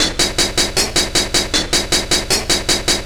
Track 11 - Hi-Hat Rhythm.wav